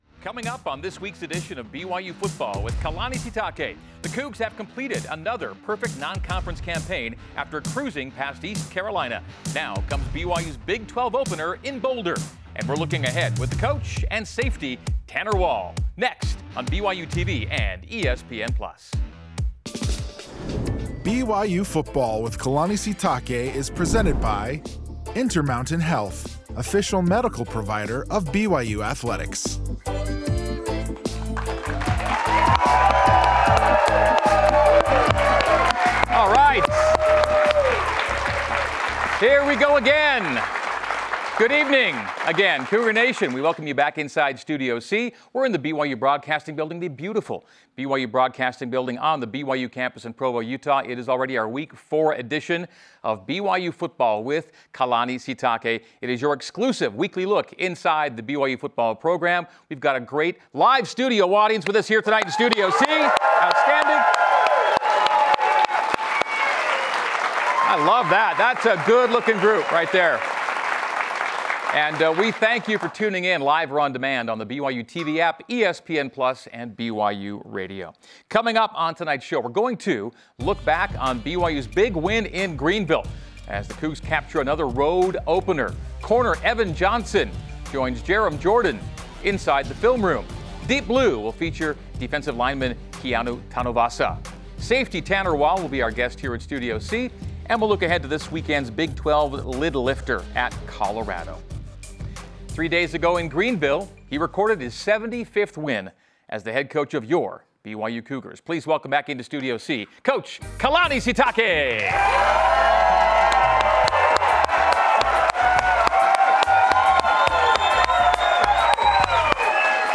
a live audience in Studio C to discuss BYU football.